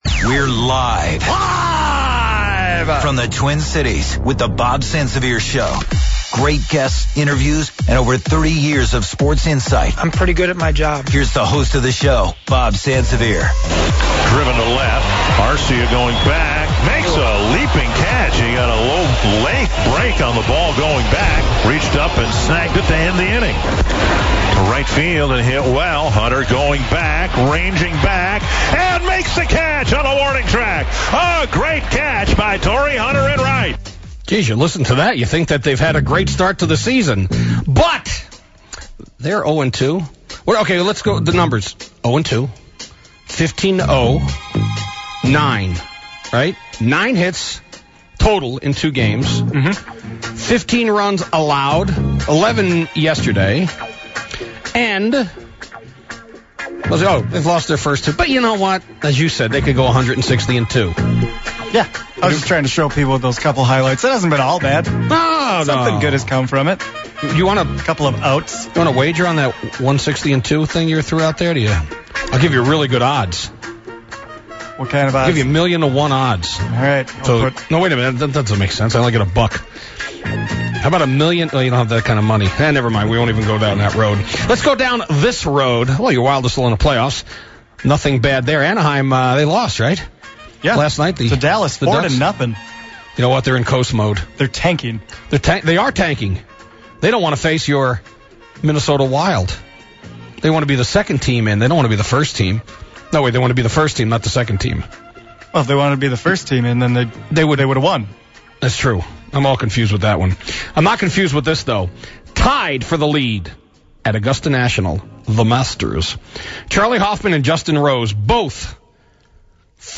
in studio to talk fantasy football